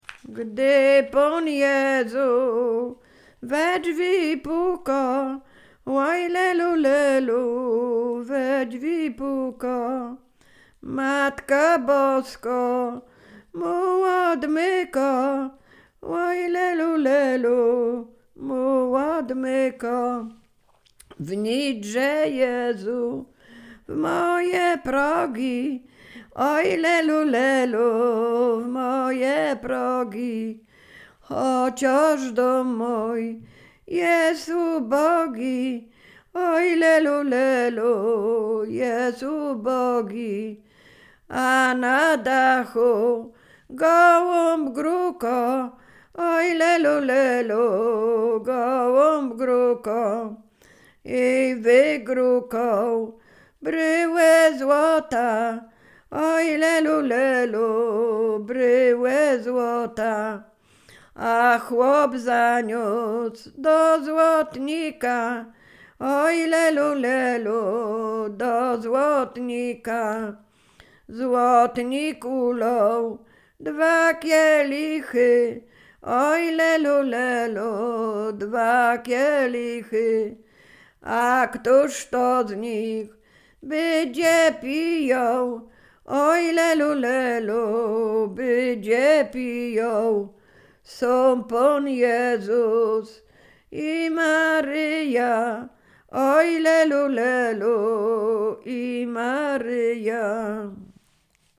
Sieradzkie
Wielkanocna
wielkanoc wiosenne wiosna kolędowanie wiosenne